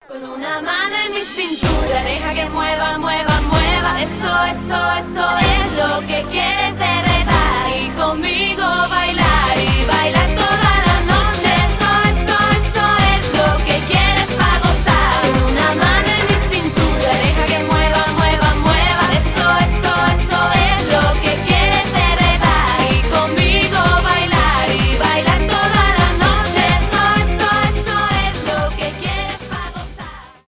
танцевальные
зажигательные